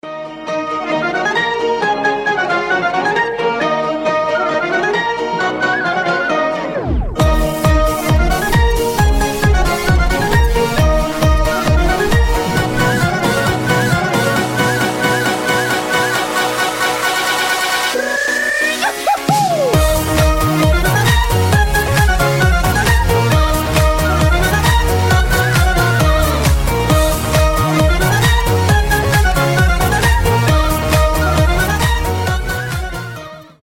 • Качество: 320, Stereo
веселые
без слов
украинские
дудка
Озорная фолк музыка